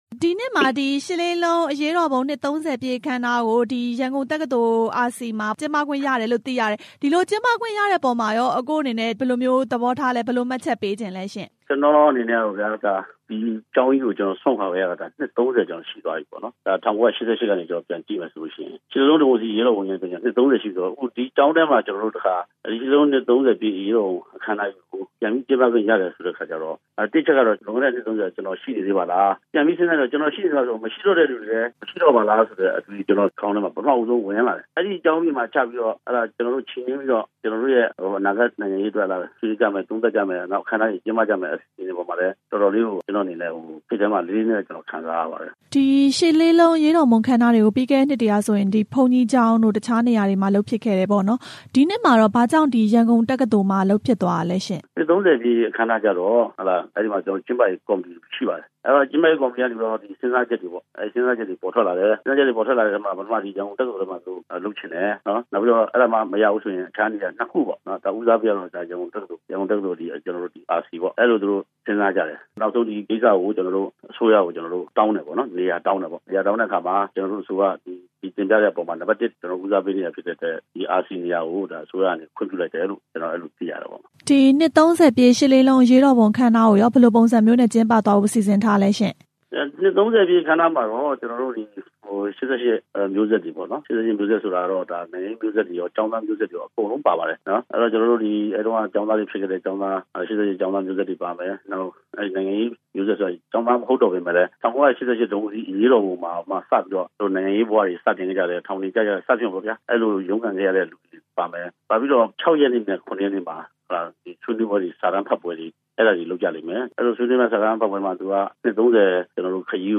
ရှစ်လေးလုံး နှစ် ၃ဝ ပြည့် ကျင်းပမယ့်အကြောင်း မေးမြန်းချက်
မေးမြန်းခန်း